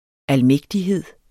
Udtale [ alˈmεgdiˌheðˀ ]